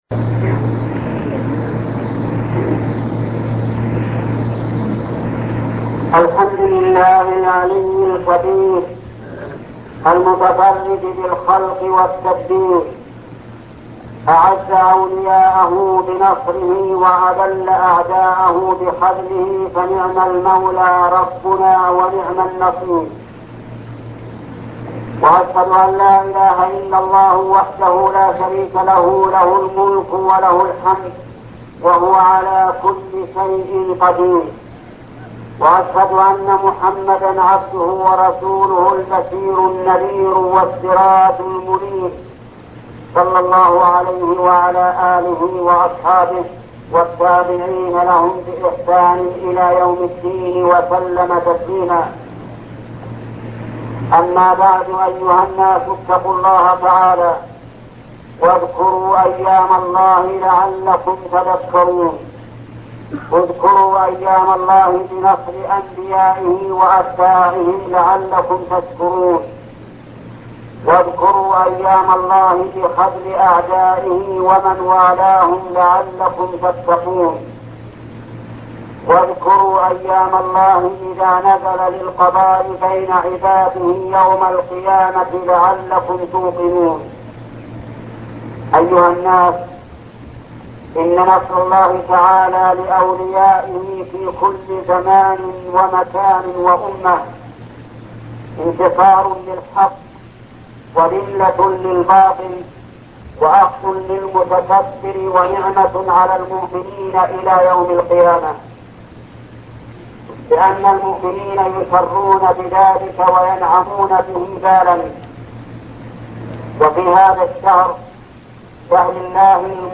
خطبة قصة موسى مع فرعون الشيخ محمد بن صالح العثيمين